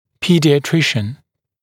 [ˌpiːdɪə’trɪʃn][ˌпи:диэ’тришн]педиатр